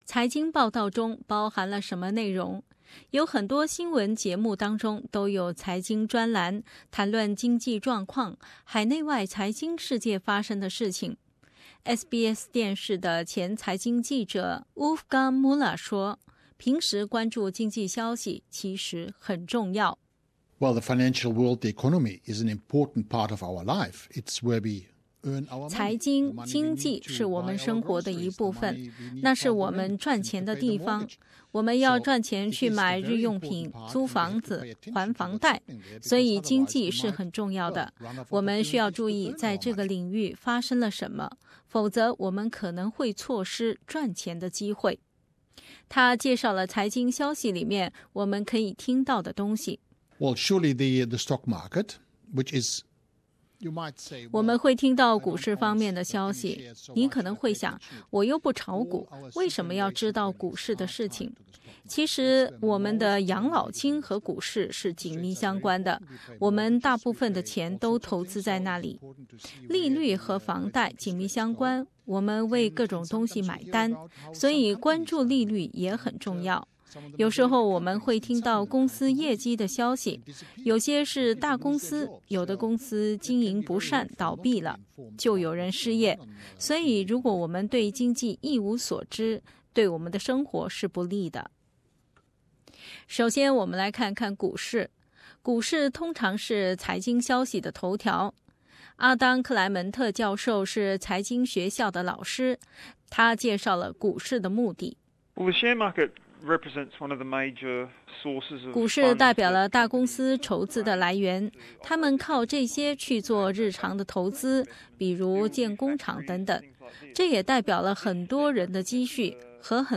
到底看得懂财经新闻和我们的生活有什么关系？ 在接下来的节目中，SBS的财经记者，澳大利亚的大学教授等专家，将为你深入浅出地介绍相关知识，并告诉你，这些经济话题和你的生活有什么关系。